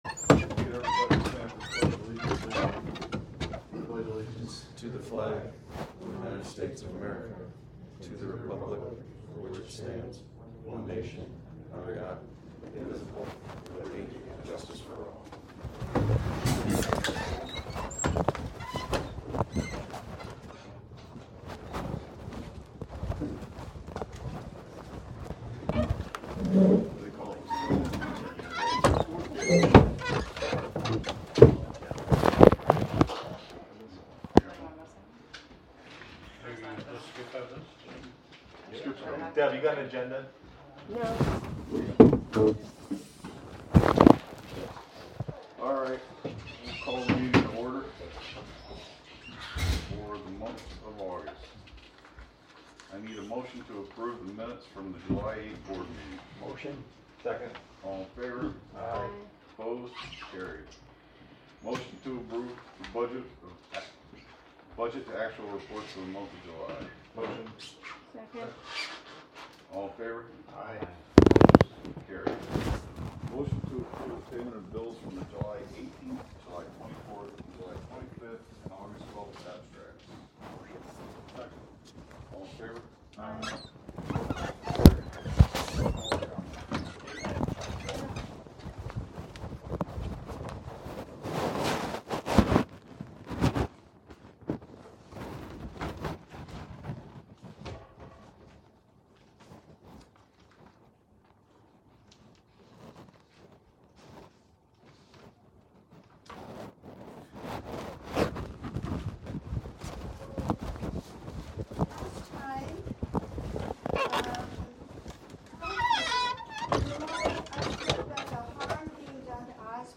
Live from the Village of Philmont: Planning Board Meeting (Audio)